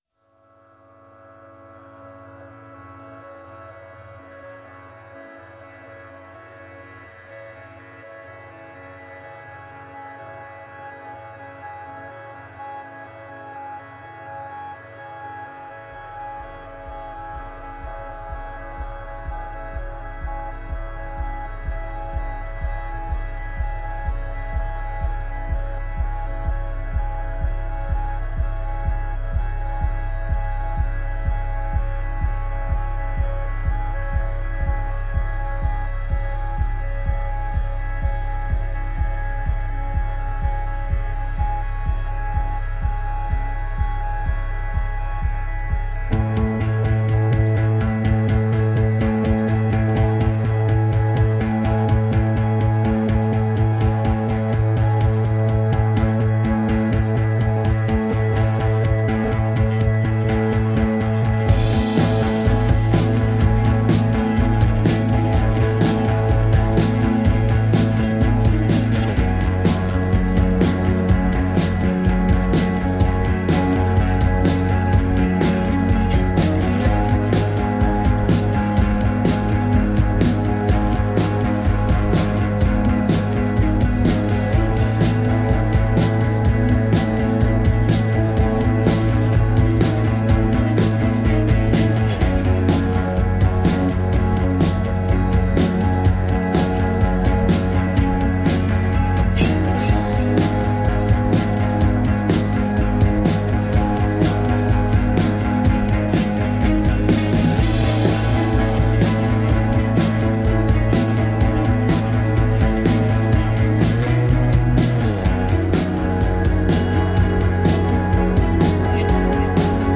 Groovy, french downtempo electro-rock songs.
Tagged as: Electro Rock, Other, Woman Singing Electro Pop